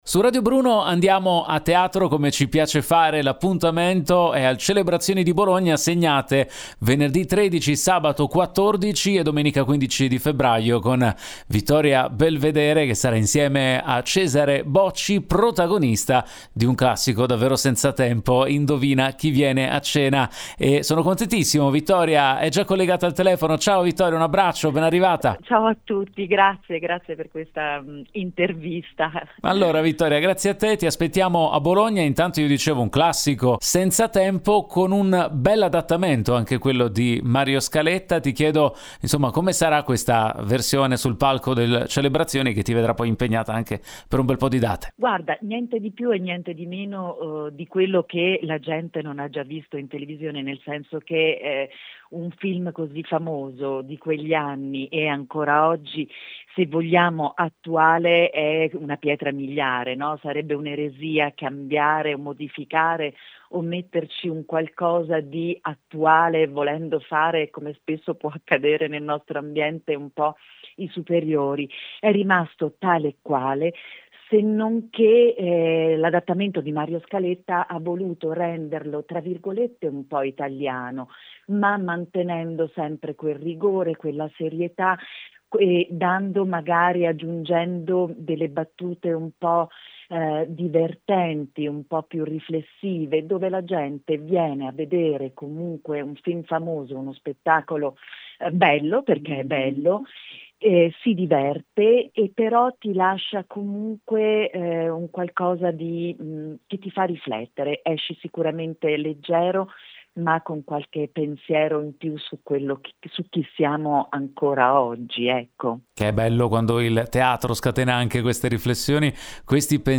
raggiunta al telefono